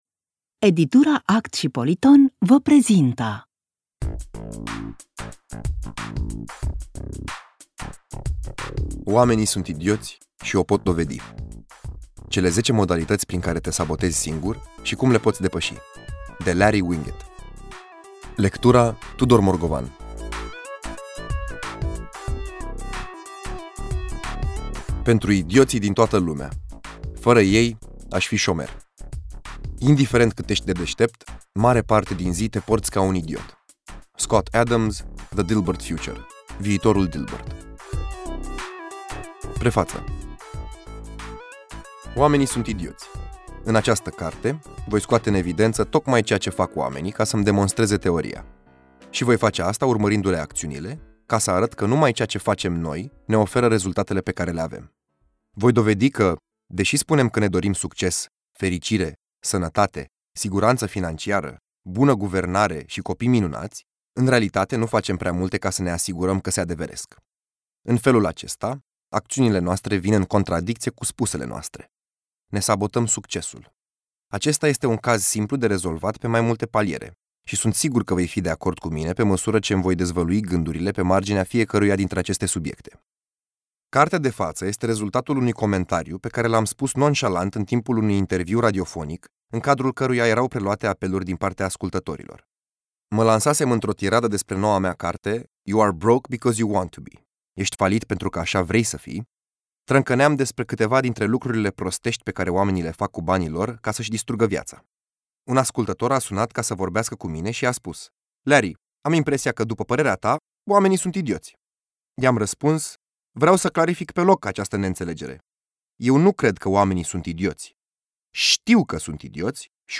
Audiobook
Ascultă fragment gratuit